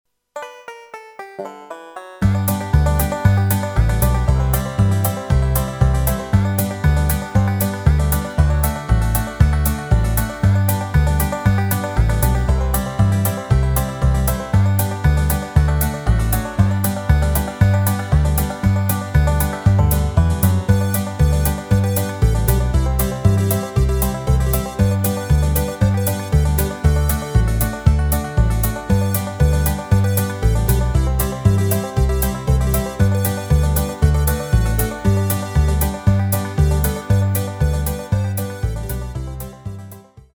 Rubrika: Pop, rock, beat
Předehra: banjo